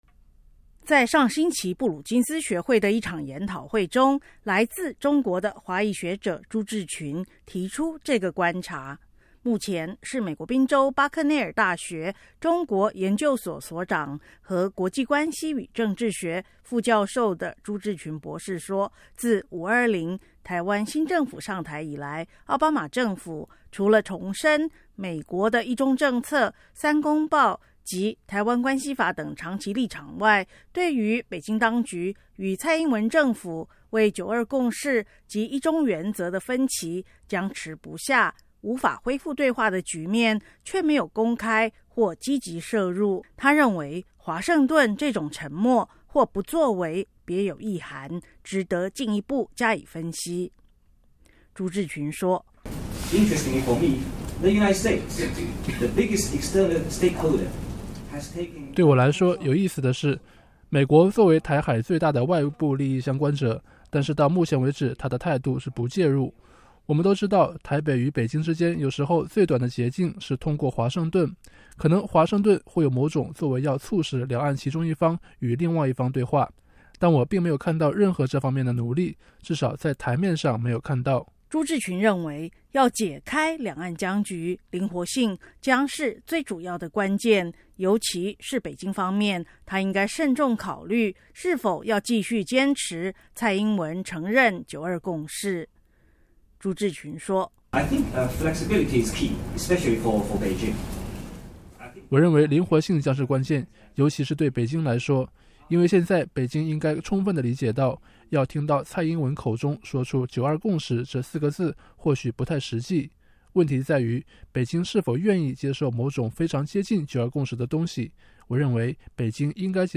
布鲁金斯学会专家讨论台海两岸关系